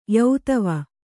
♪ yautava